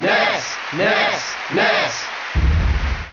File:Ness Cheer JP Melee.ogg
Ness_Cheer_JP_Melee.ogg.mp3